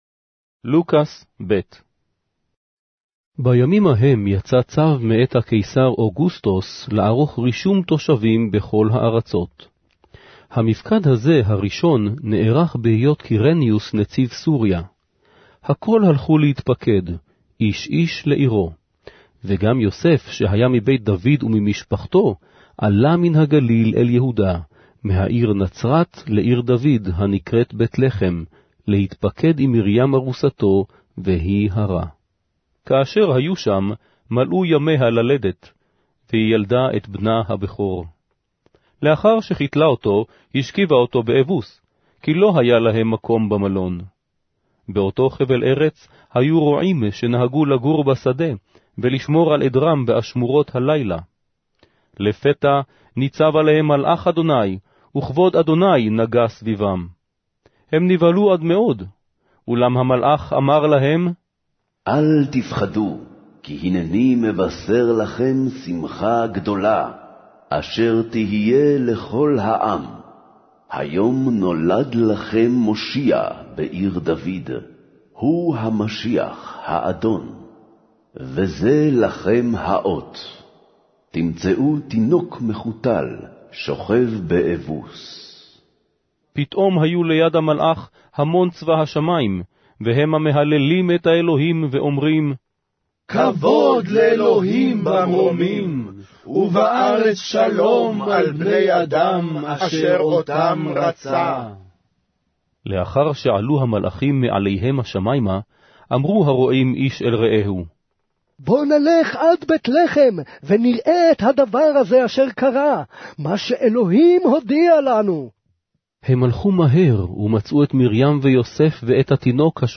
Hebrew Audio Bible - Luke 6 in Mkjv bible version